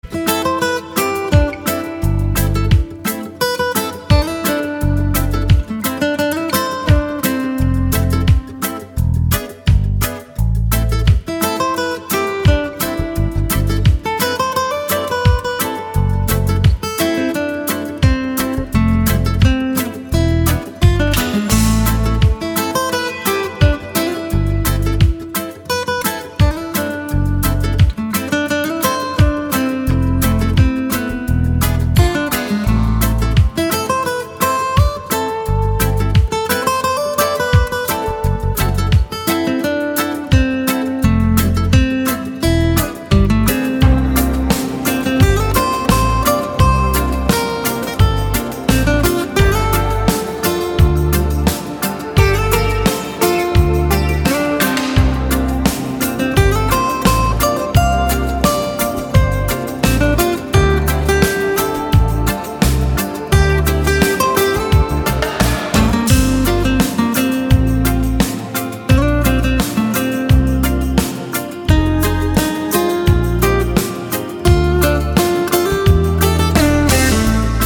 • Качество: 192, Stereo
гитара
громкие
спокойные
без слов
инструментальные
jazz